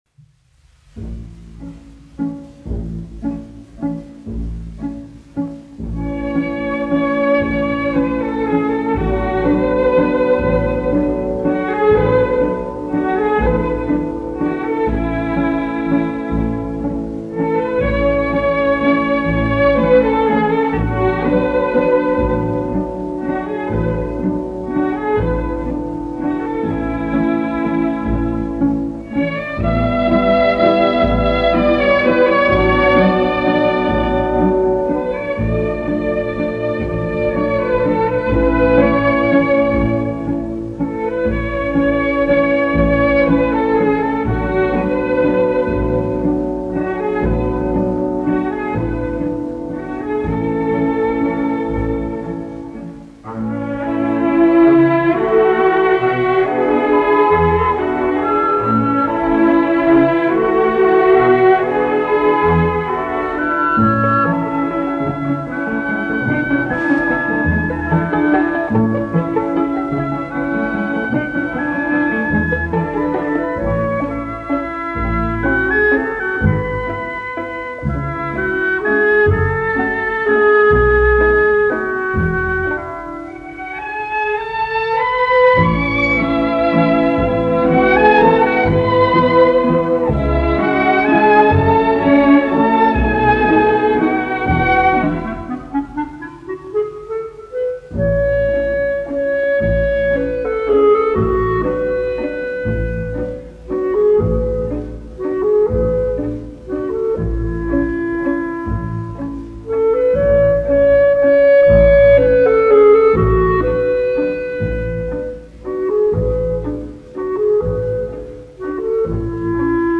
Jansons, Arvīds, 1914-1984, diriģents
Ленинградская государственная филармония. Академический симфонический оркестр, izpildītājs
1 skpl. : analogs, 78 apgr/min, mono ; 25 cm
Orķestra mūzika
Latvijas vēsturiskie šellaka skaņuplašu ieraksti (Kolekcija)